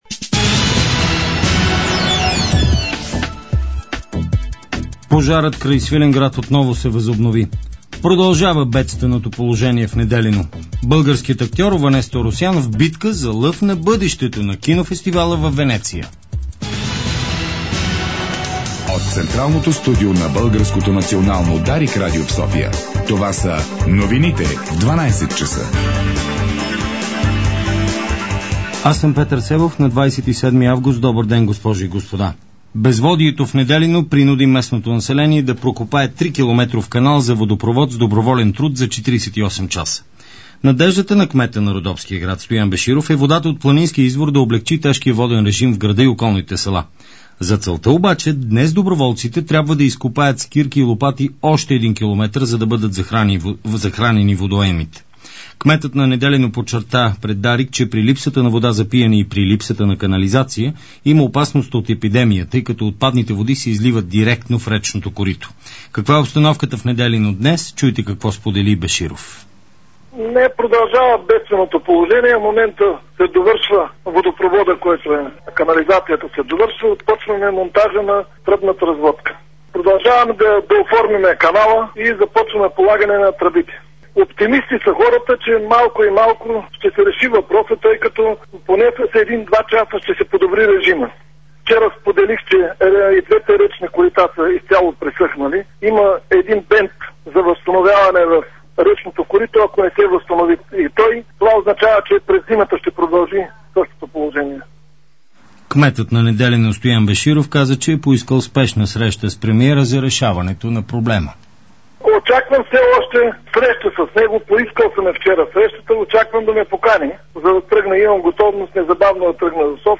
Обедна информационна емисия